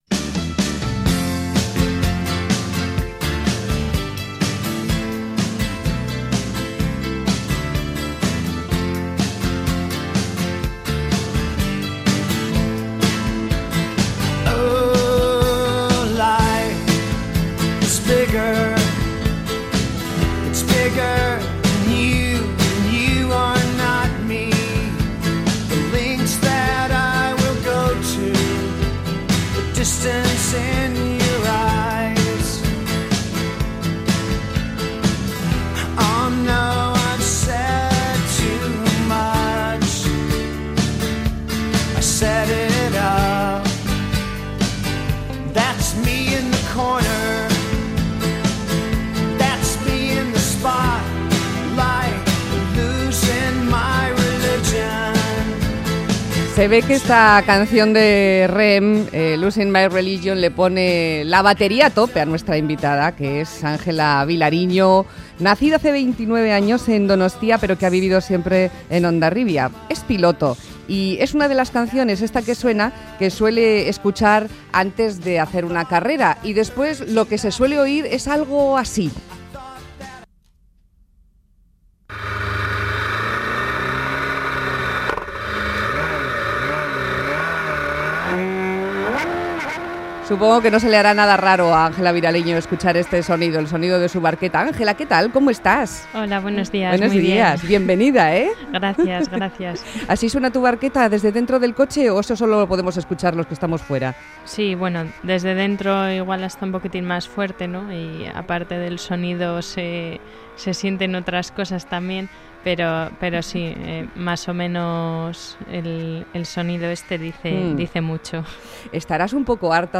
Entrevista personal